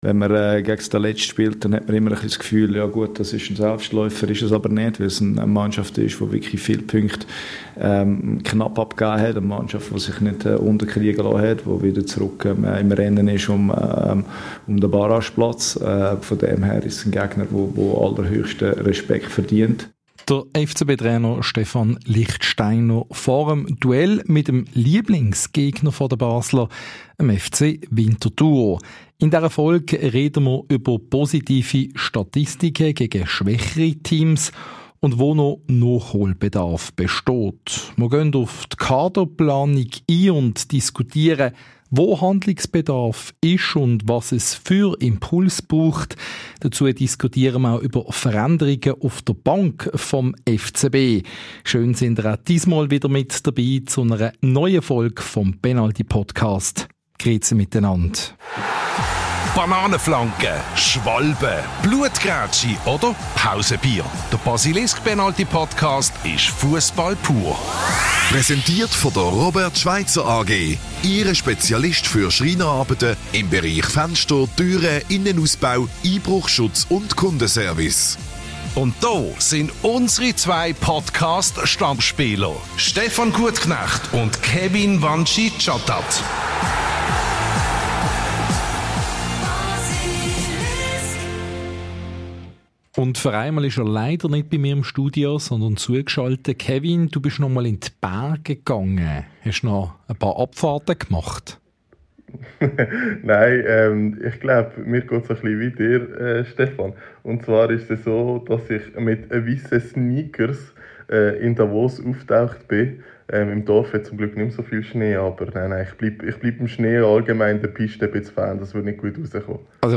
Unser Podcast-Duo diskutiert über die wiedergewonnene Stärke bei Standardsituationen und die Kaderplanung. Auf welchen Positionen besteht Handlungsbedarf und was für Veränderungen sind nötig?